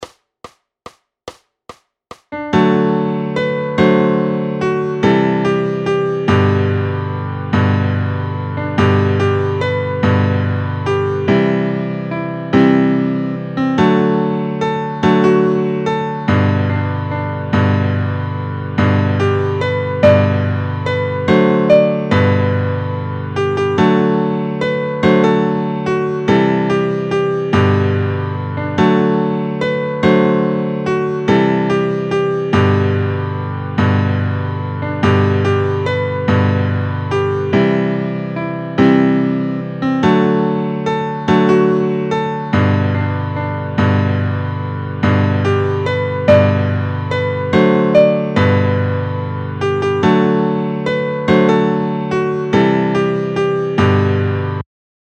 Noty na snadný klavír.
Formát Klavírní album
Hudební žánr Irská lidová hudba